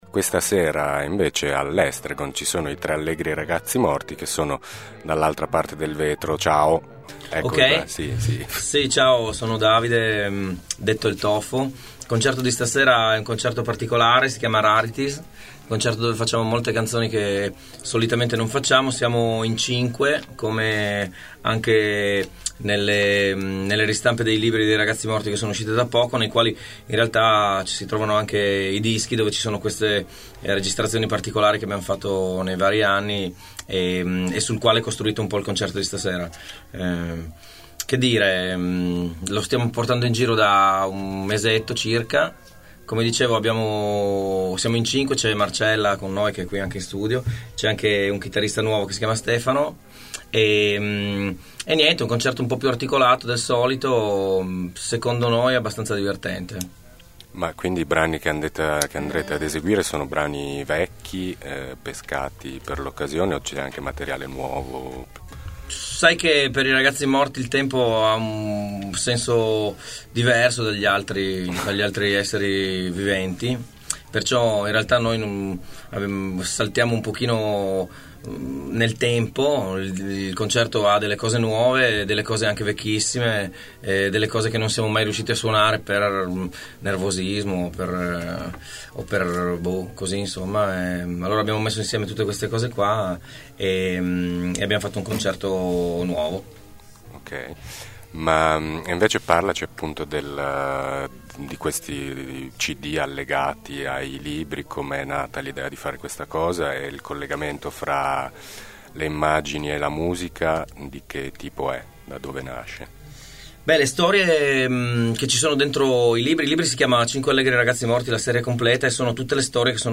In occasione del loro concerto all’Estragon sabato scorso, sono venuti a trovarci a Thermos i Tre Allegri Ragazzi Morti.
tre-allegri-ragazzi-morti-intervista-1.mp3